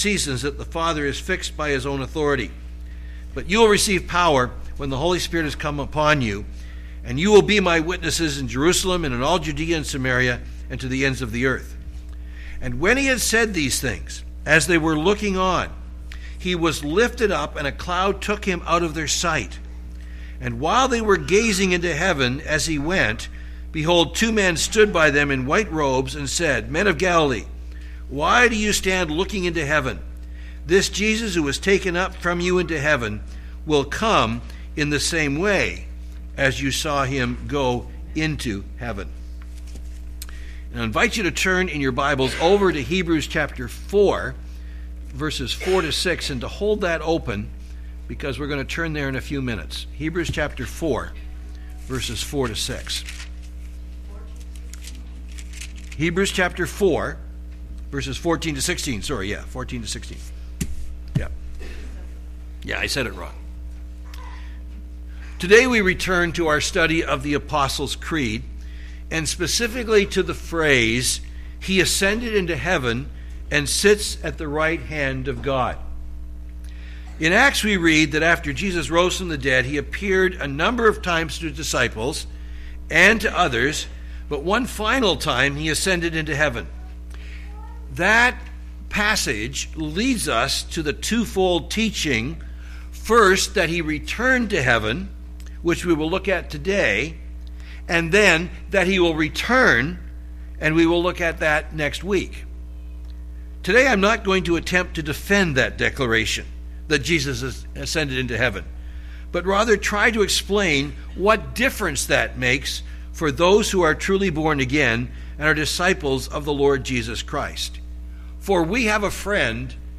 Sermons | Bethel Bible Church